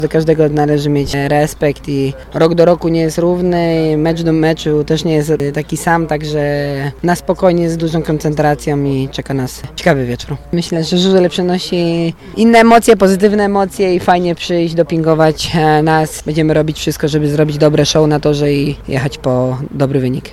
Rok do roku nie jest równy, żaden mecz też nie jest taki sam – mówi w rozmowie z Radiem Lublin kapitan lubelskiej drużyny Bartosz Zmarzlik.